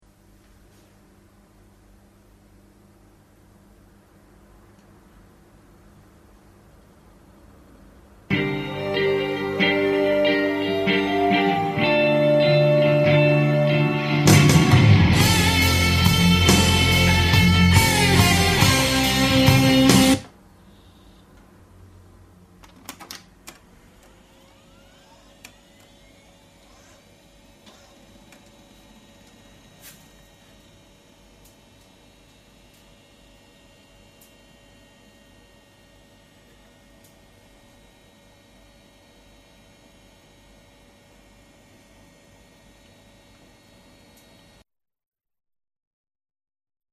SILENT PC
Dopo tutti questi accorgimenti, il PC non è putroppo diventato completamente "muto", ma le sue fastidiosi emissioni rumorose sono state notevolmente ridotte!
Per darvi un'idea di quanto rumore fa il mio PC, ho provato a fare un test registrando con il mio minidisc. Montato il microfono più o meno nella posizione in cui tengo la testa davanti al monitor, e rivolto verso di esso, ho registrato prima di tutto il "silenzio" della stanza, poi ho acceso lo stereo regolando il volume al livello che imposto di solito, in modo da dare un riferimento "assoluto"al rumore del computer. In seguito, spento lo stereo, ho acceso il PC.
SilentPC.mp3